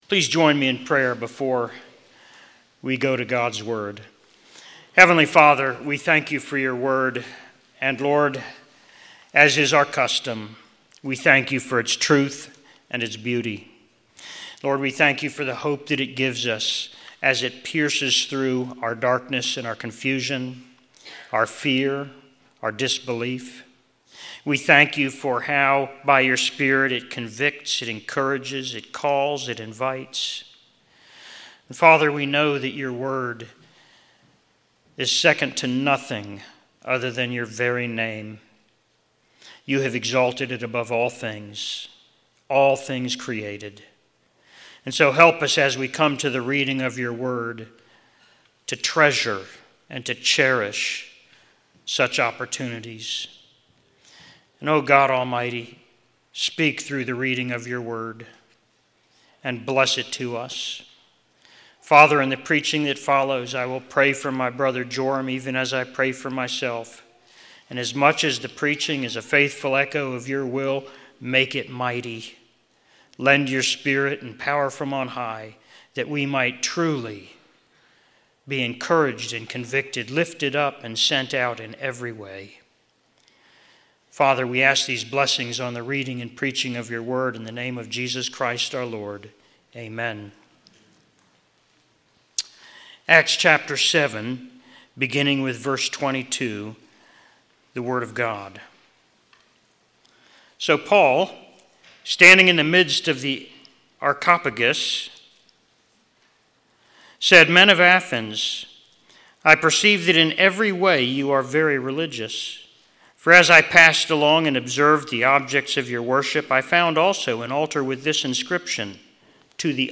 Missions Conference: Guest Speaker